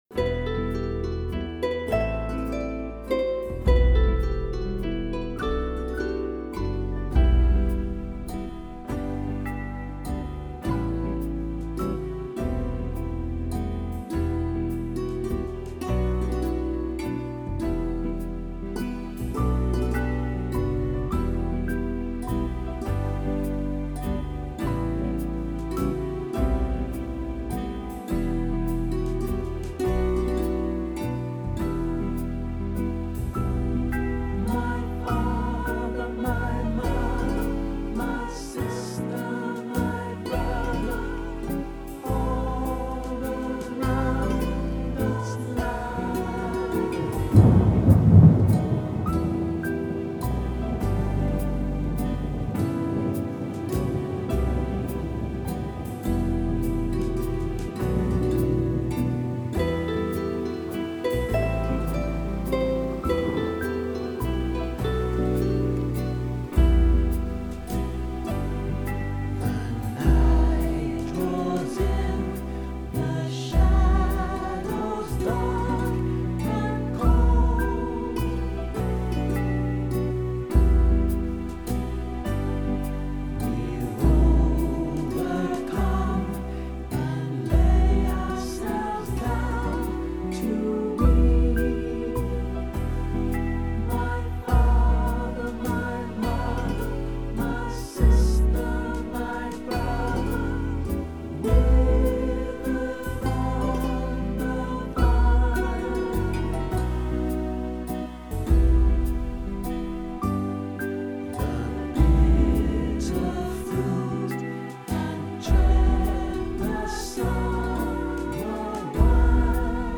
Song style: folk
smoothly and sadly
Download the part vocal (harmony) version of the song